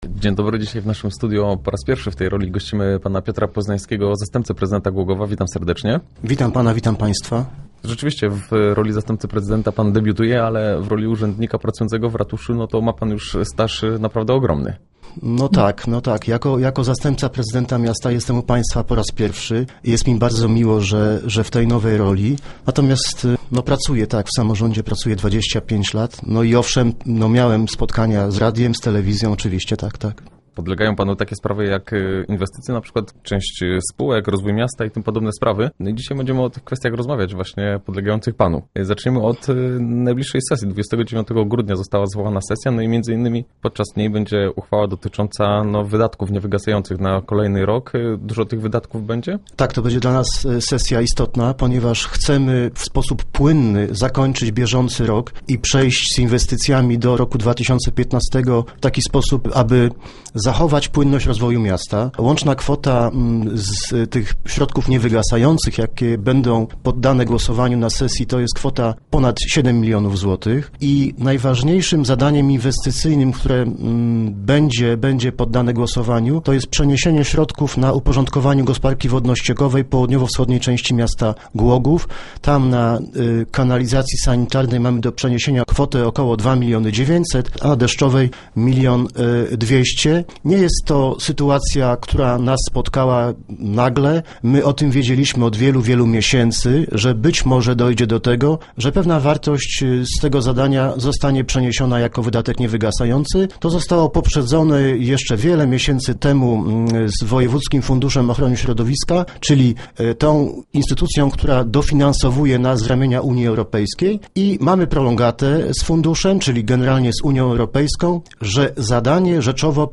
Start arrow Rozmowy Elki arrow Poznański: W styczniu budżet będzie uchwalony
Między innymi o tym rozmawiamy z Piotrem Poznańskim, zastępcą prezydenta miasta.